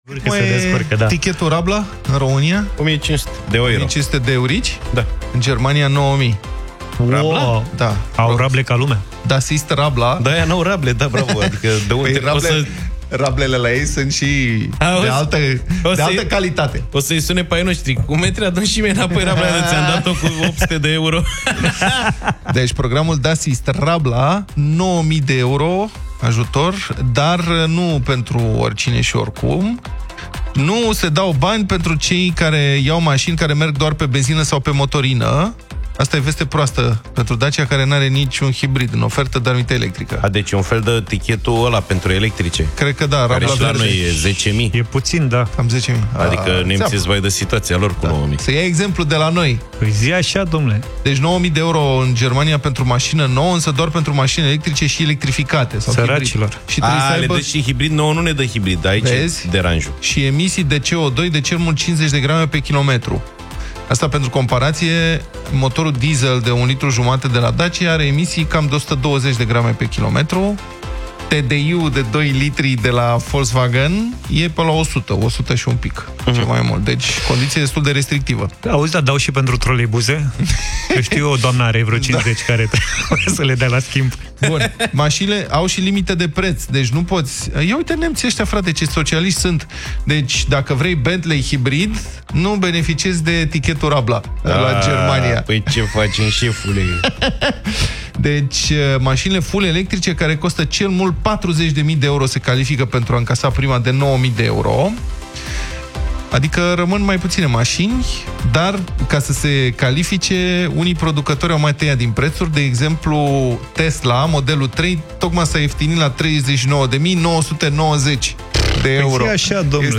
au vorbit despre acest subiect în Deșteptarea.